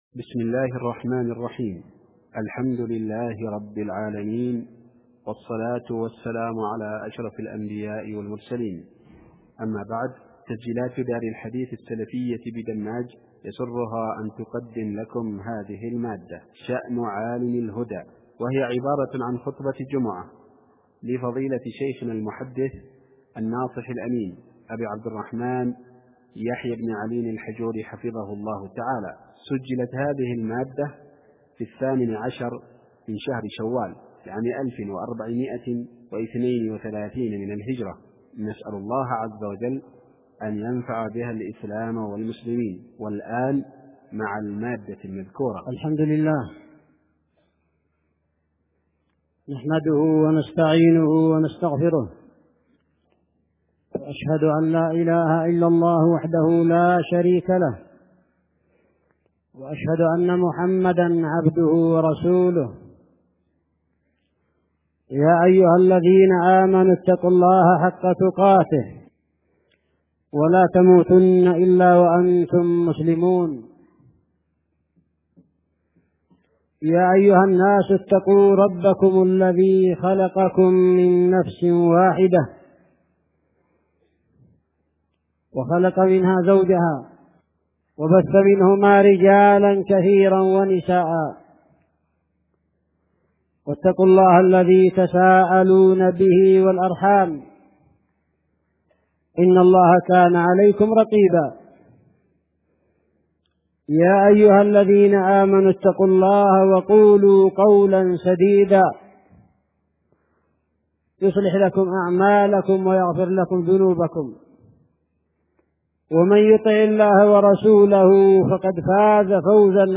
خطبة جمعة بعنوان (( شأنُ عالمِ الهدى ))